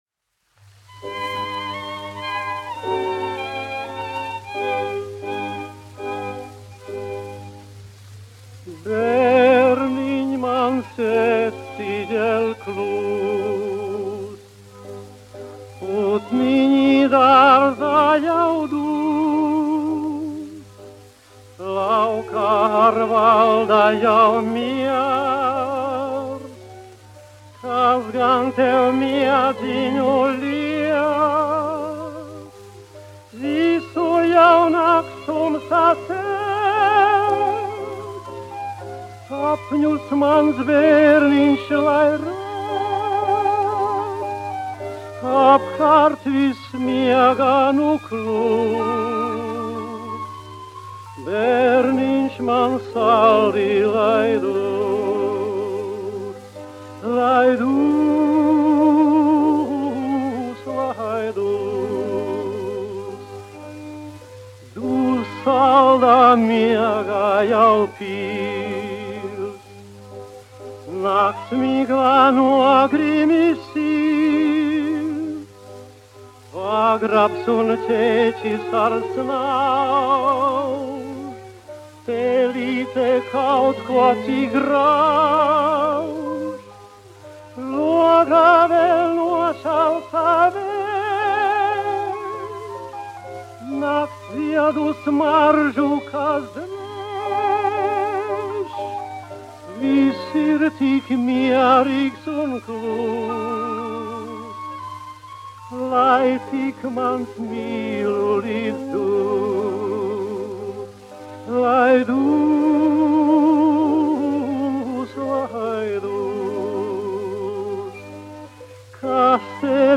1 skpl. : analogs, 78 apgr/min, mono ; 25 cm
Dziesmas (augsta balss) ar orķestri
Latvijas vēsturiskie šellaka skaņuplašu ieraksti (Kolekcija)